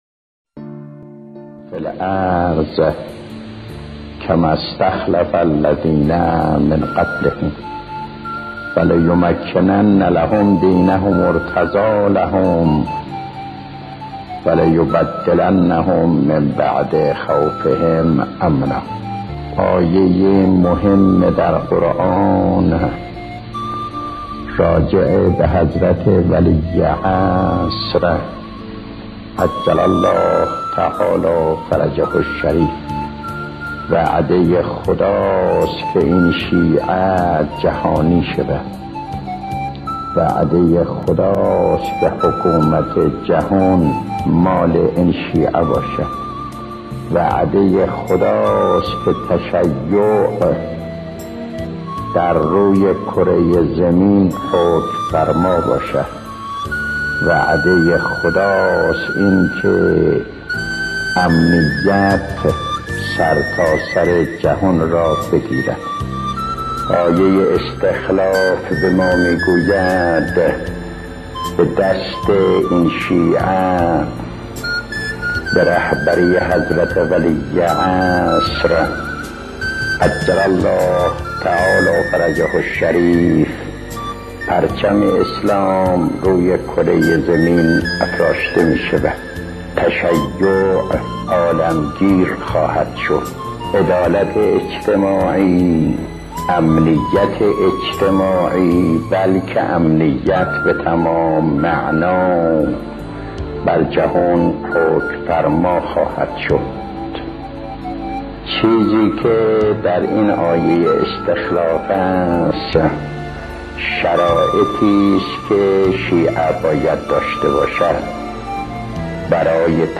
سخنانان ارزشمند استاد آیت‌الله مظاهری با موضوع وعده خداست که این شیعه بیاید